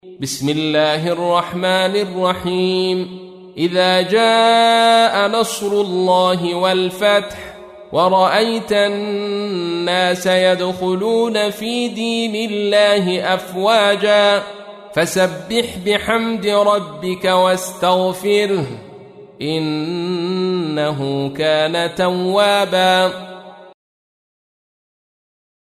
تحميل : 110. سورة النصر / القارئ عبد الرشيد صوفي / القرآن الكريم / موقع يا حسين